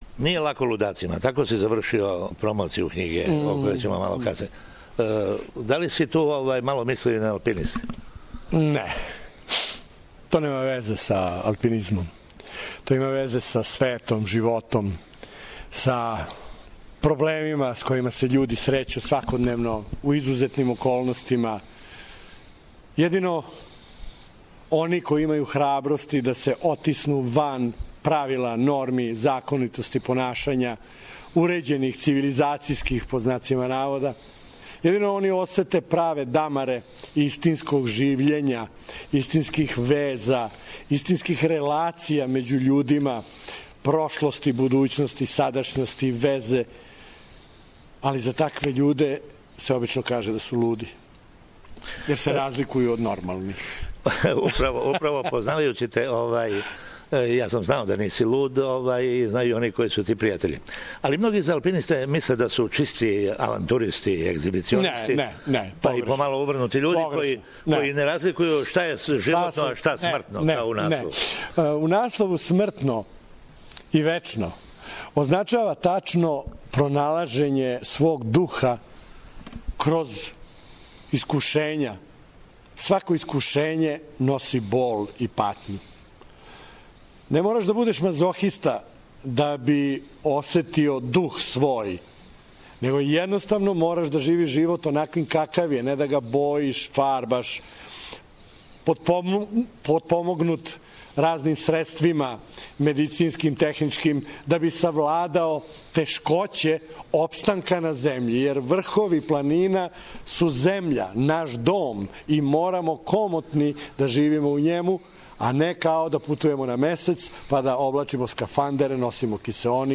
у интервјуу